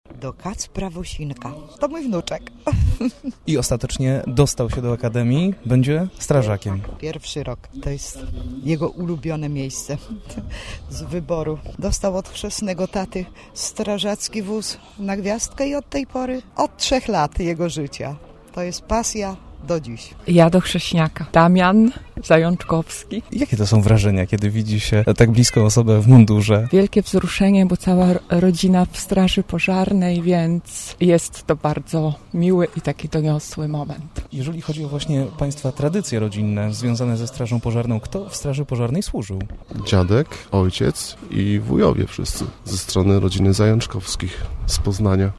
Akademia Pożarnicza rozpoczęła rok akademicki.